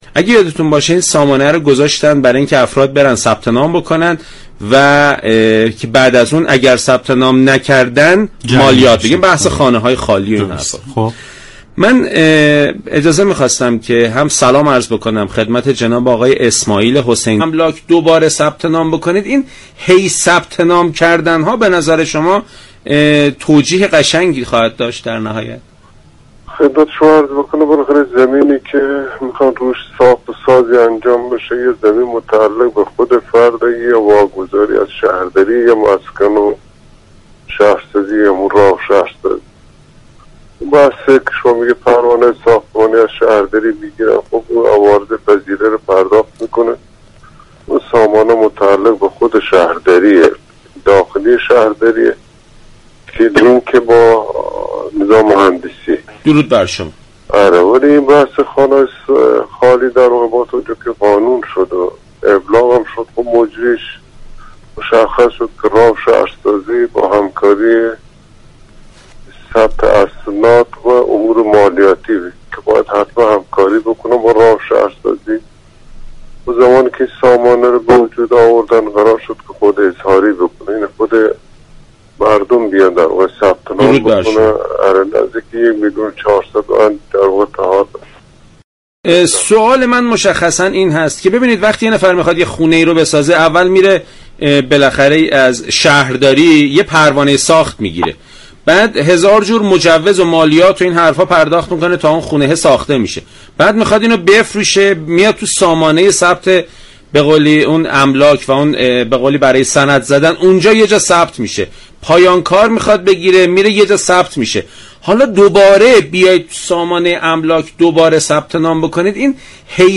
عضو كمیسیون عمران مجلس در برنامه سلام صبح بخیر رادیو ایران گفت : دستگاه های مختلف در مشخص كردن خانه های خالی متحد شوند